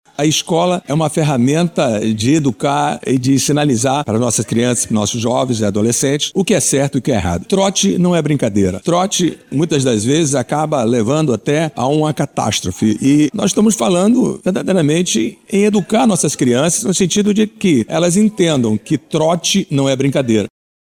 O intuito é conscientizar os alunos sobre os riscos de uma ligação falsa para serviços essenciais, como destaca o autor da proposta, o vereador Mitoso.